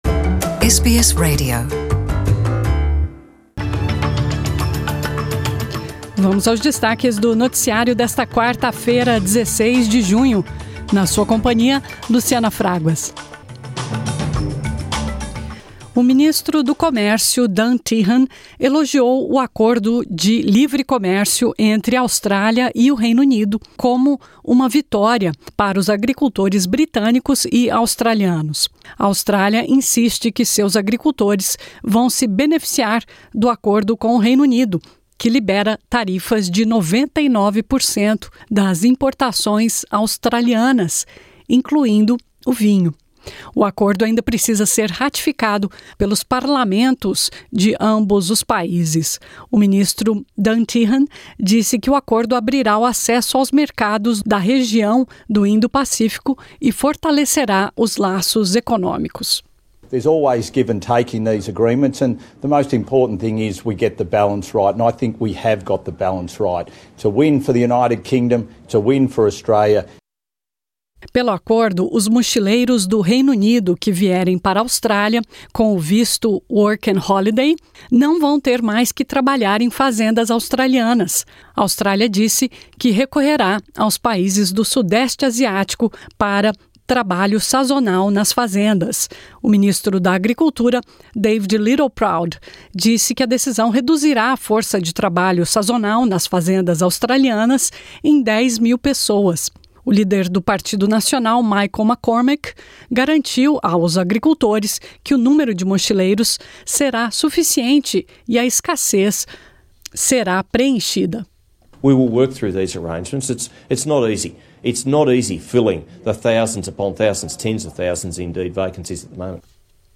A Austrália diz que recorrerá aos países do sudeste asiático (Brunei, Mianmar, Camboja, Indonésia, Laos, Malásia, Filipinas, Cingapura, Tailândia e Vietnã) para preencher o gap que será deixado pelos britânicos e anunciou a criação de um novo 'visto da agricultura' que será lançado para esses países até o final do ano. Confira esses e outros destaques do noticiário de hoje.